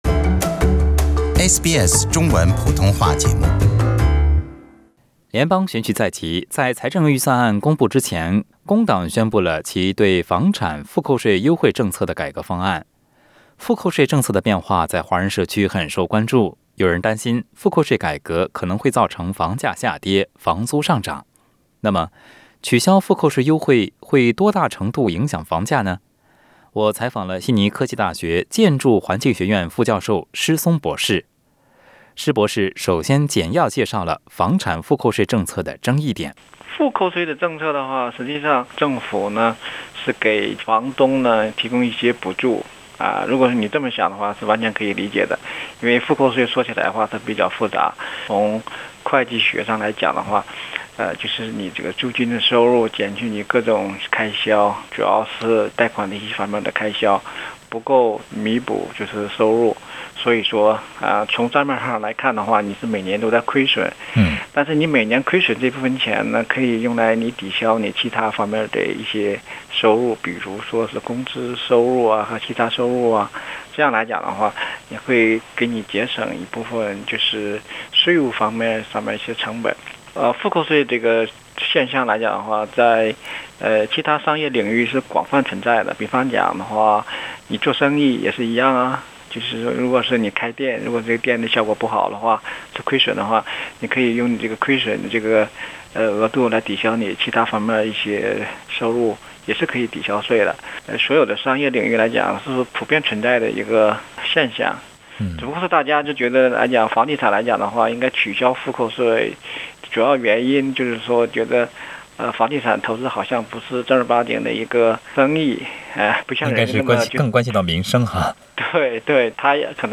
（嘉宾观点，不代表本台立场） 关注更多澳洲新闻，请在Facebook上关注SBS Mandarin，或在微博上关注澳大利亚SBS广播公司 READ MORE 【联邦大选2019】”性别配额”遭质疑，两党重推女性代表 【观点】取消负扣税将打击工薪阶层收入 资产负扣税，谁是最大获益者？